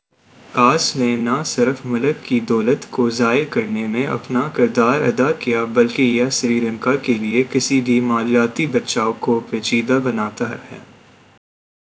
Spoofed_TTS/Speaker_07/272.wav · CSALT/deepfake_detection_dataset_urdu at main